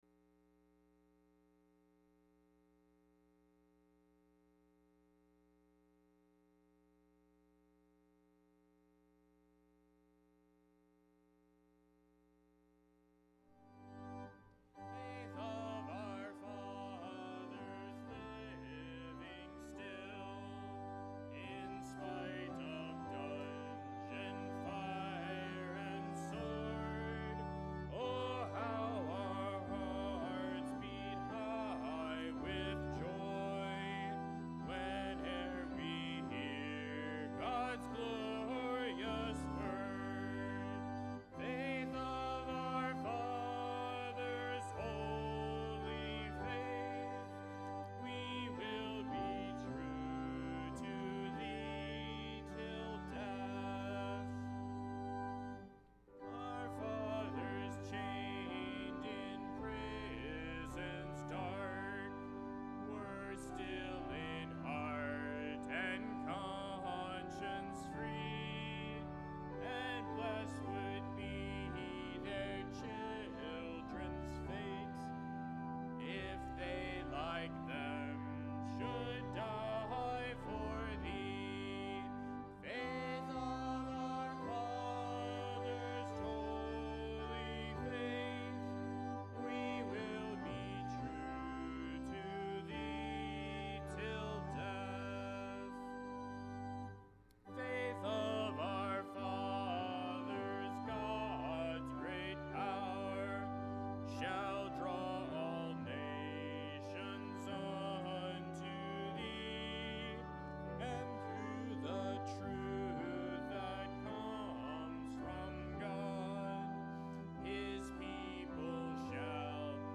1 Timothy 6:17-19 Service Type: Sunday Morning %todo_render% « 1 Timothy 6:2-10